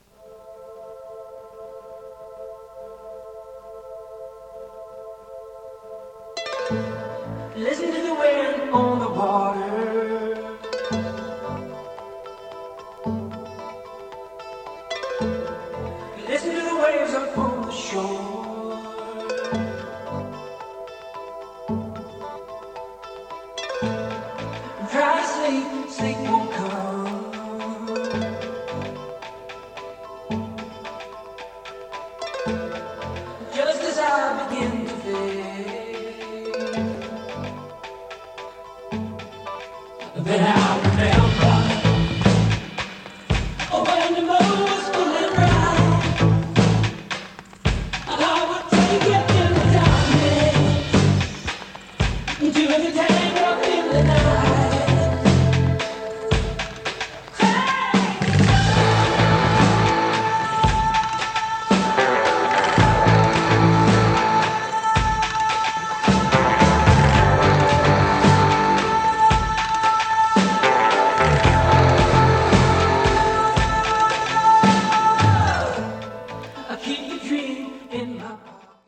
Below is a test recording made with the 482Z and played back by it:
Track System: 4-track, 2-channel stereo
Nakamichi-482Z-Test-Recording.mp3